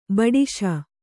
♪ baḍiśa